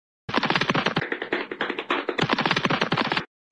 goofy ahh running sounds meme mp3 Meme Sound Effect
goofy ahh running sounds meme mp3.mp3